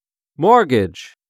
Mortgage = An agreement under which a person borrows money to buy property (a house or an apartment). Please note that the "t" is not pronounced.
mortgage.wav